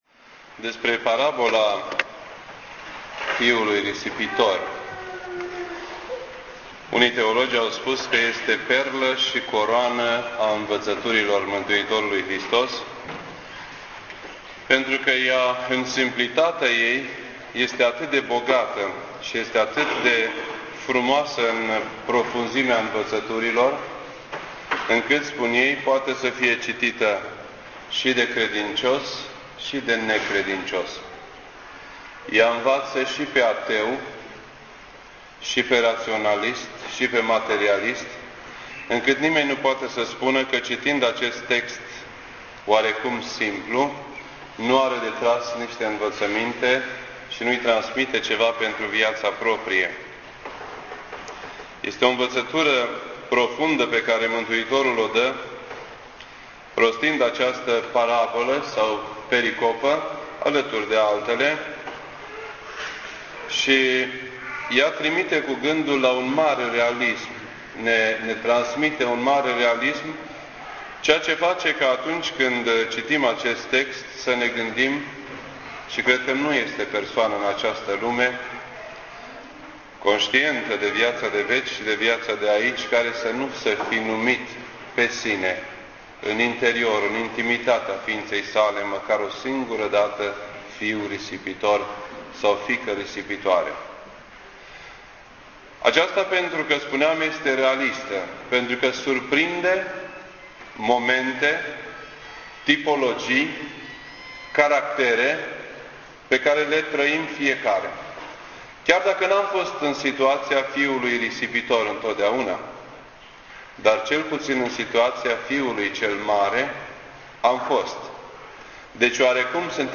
This entry was posted on Sunday, February 22nd, 2009 at 6:54 PM and is filed under Predici ortodoxe in format audio.